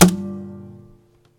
Category ⚡ Sound Effects
ding hit metal ring ting tone sound effect free sound royalty free Sound Effects